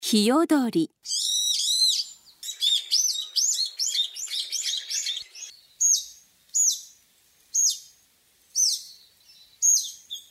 ヒヨドリ
【鳴き声】「ピーヨ、ピーヨ」のほか、「ピイピョロロ」「ピーピッピッピッ」などと鳴く。
ヒヨドリの鳴き声（音楽：172KB）
hiyodori.mp3